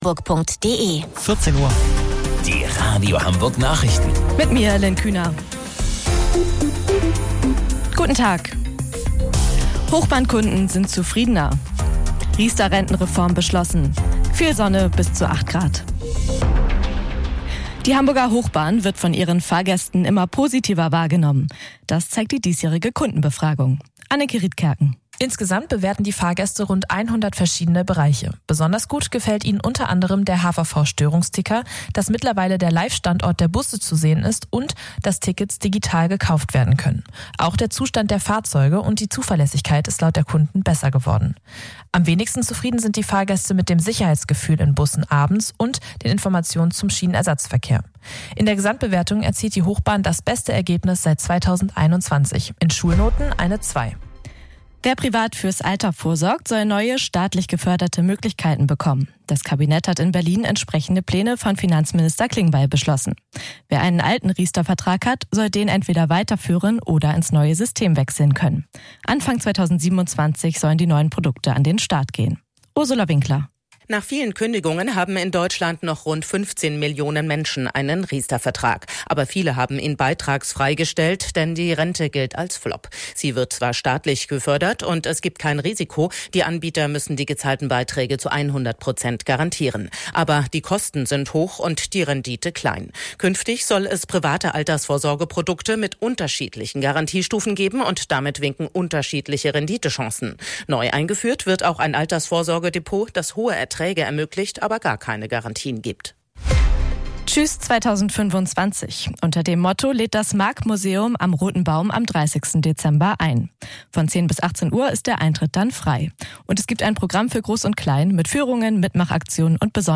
Radio Hamburg Nachrichten vom 17.12.2025 um 14 Uhr